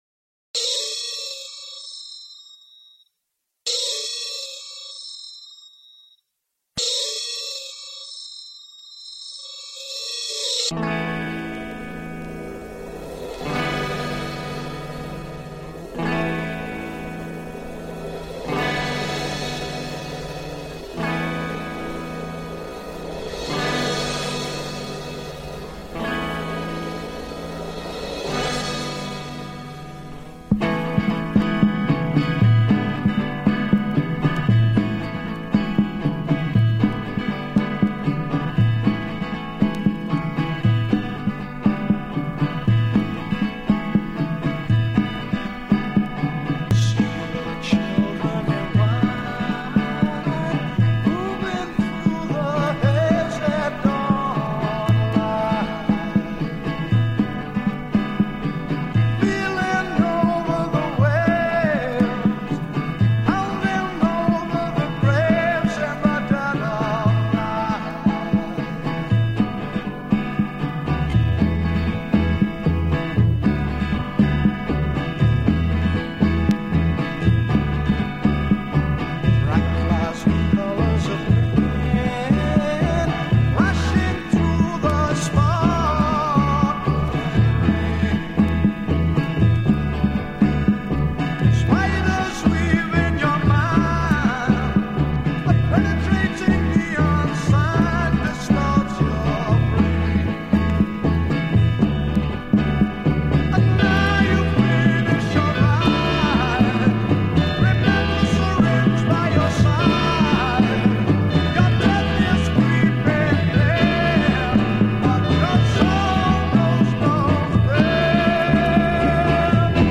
Unissued demo.